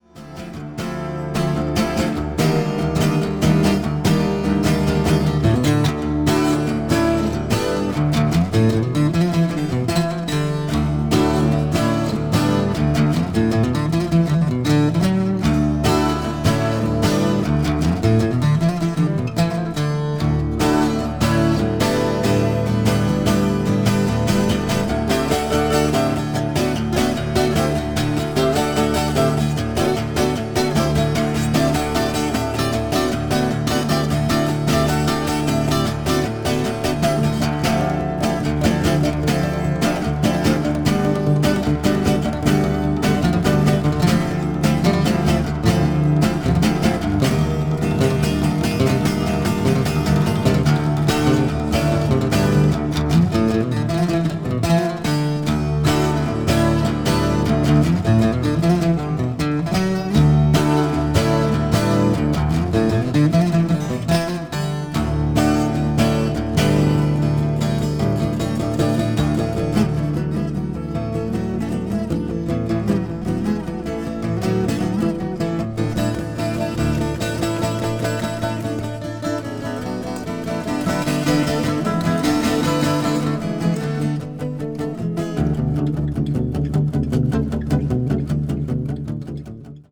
contemporary jazz   ethnic jazz   guitar solo